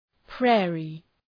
Shkrimi fonetik {‘preərı}